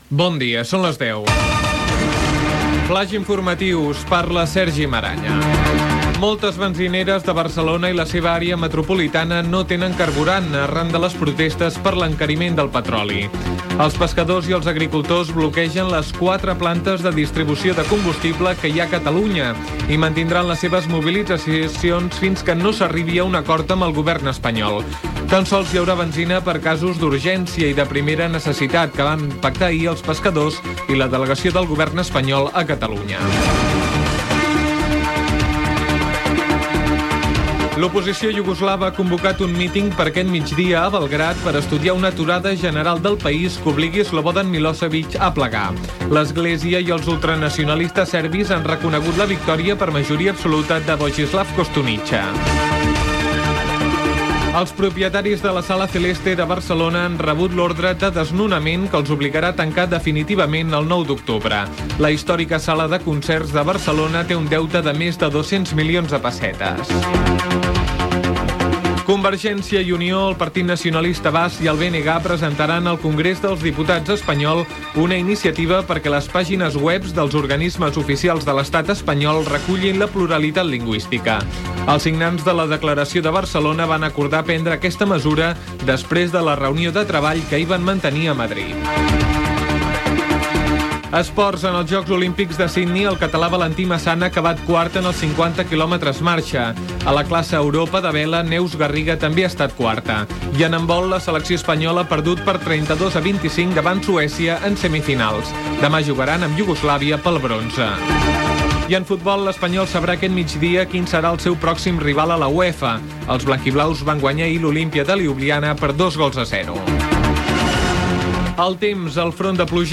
Flaix informatiu
Informatiu